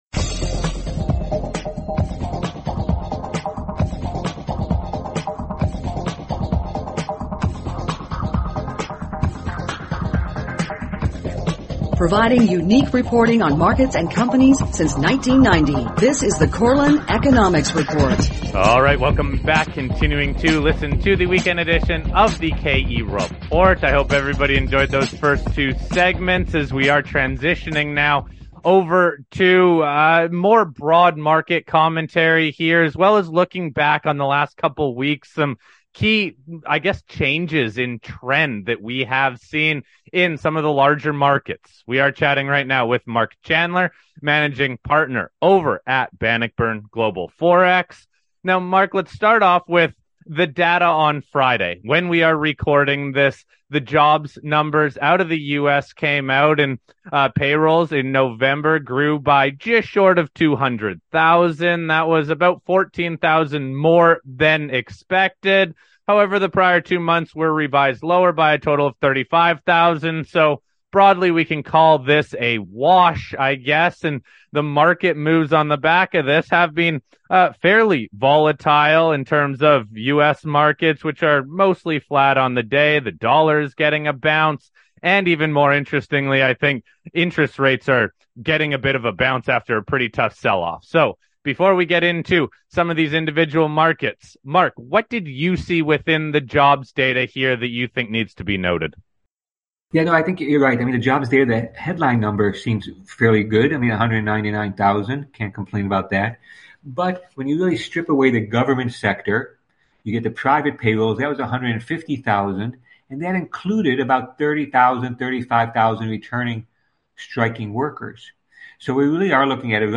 Welcome to The KE Report Weekend Show. On this Weekend’s Show we are joined by two of our favorite generalist guests who balance current economic data with market moves. We typically focus on how moves in the largest markets filter down to the other markets we follow closely.